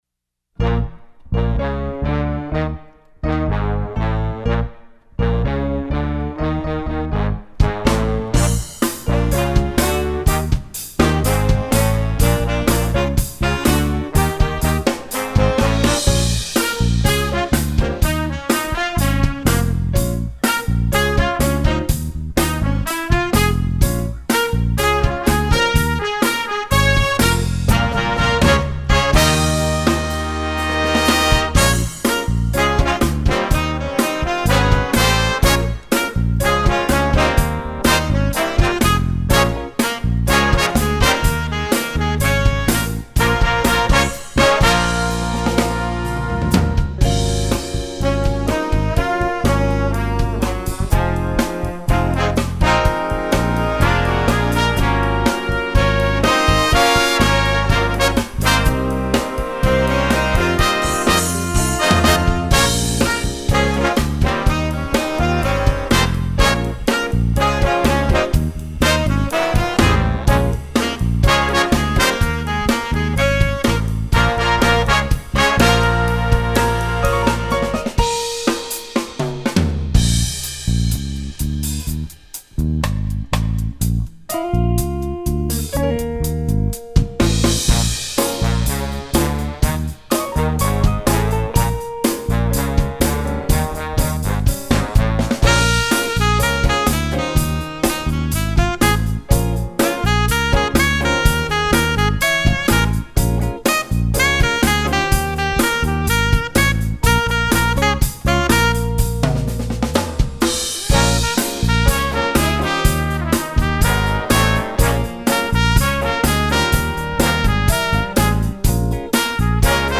jazz, rock